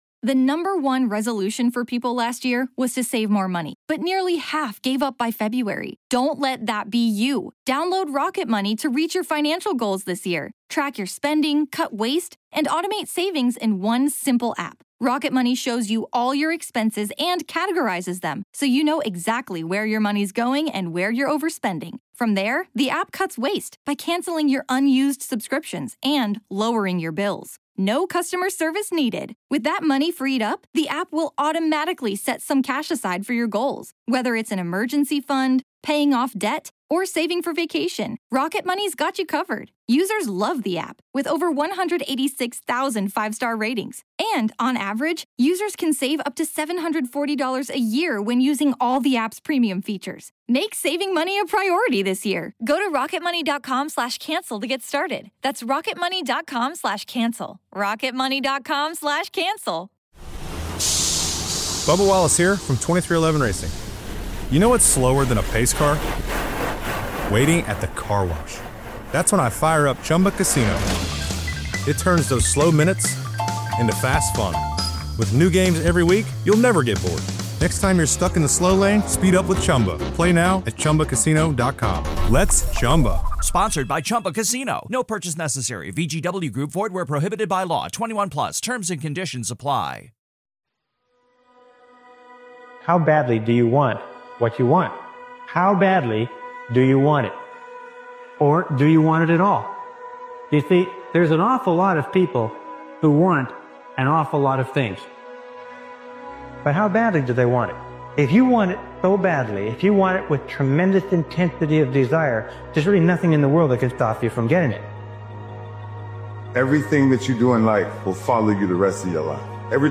This intense motivational speeches compilation forces you to confront your level of commitment—because desire without action means nothing.